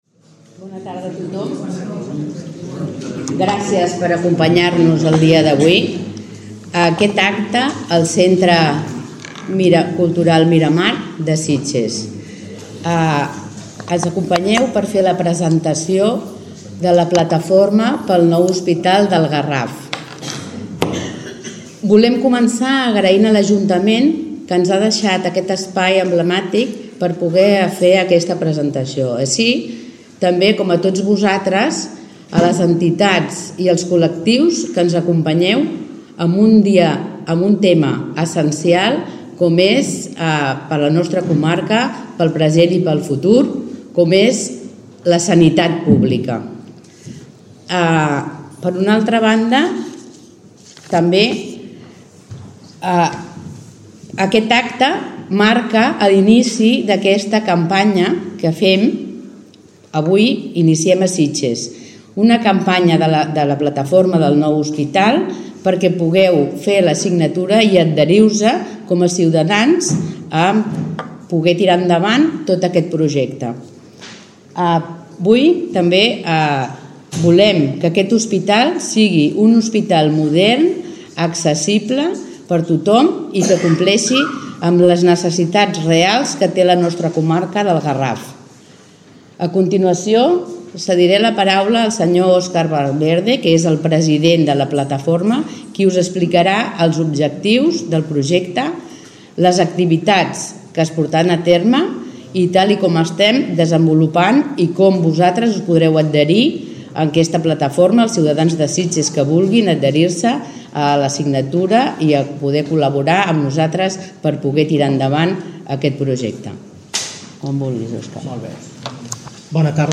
Representants de la Plataforma pel Nou Hospital Comarcal han presentat davant la ciutadania de Sitges la situació actual del projecte del nou centre sanitari. L’objectiu era informar de tots els passos realitzats fins ara des que es va crear la plataforma ara fa dos anys i incentivar els sitgetans a adherir-se a la campanya mitjançant signatures per tal d’augmentar la pressió a les administracions pertinents. A hores d’ara la plataforma ha aconseguit recollir més de setze mil signatures.